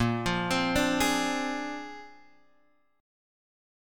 A# 9th